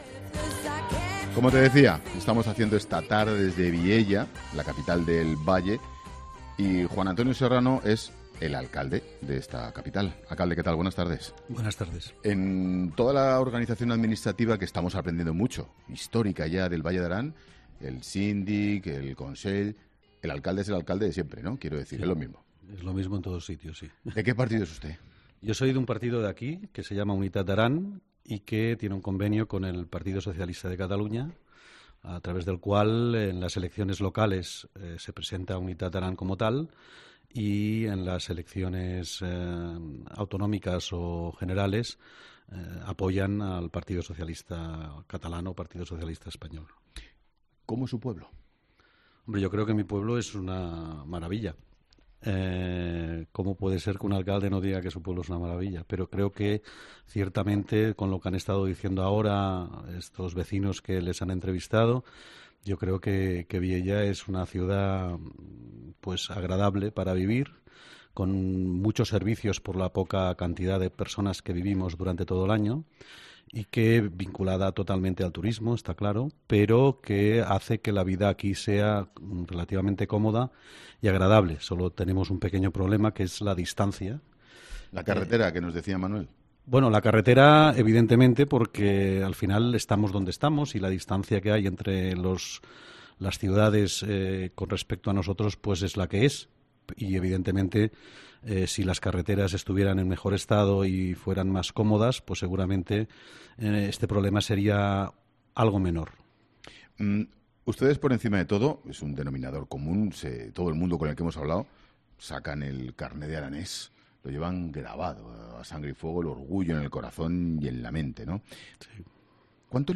'LA TARDE' DESDE EL VALLE DE ARÁN
Juan Antonio Serrano, alcalde de Vielha